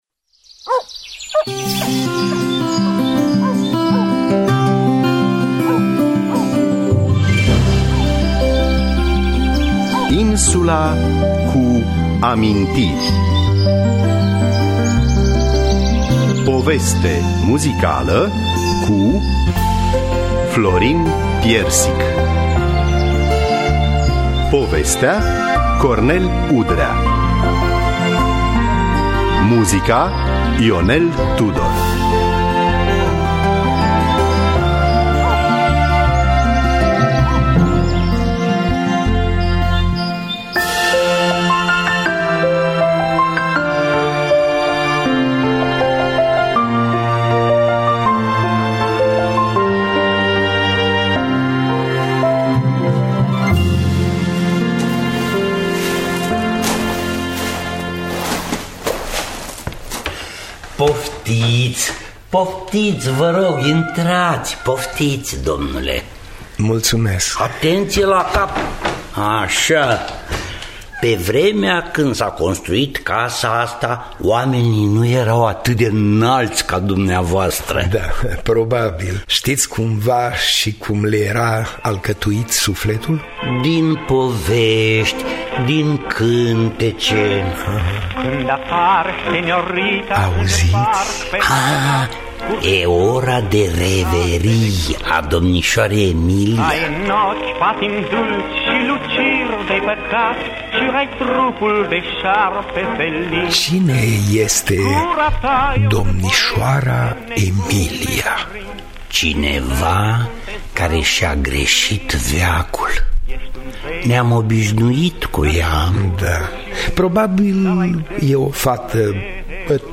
Insula cu amintiri – Poveste muzicală… cu Florin Piersic
În distribuție: Florin Piersic, Emilia Popescu, Adriana Trandafir, Valentin Uritescu.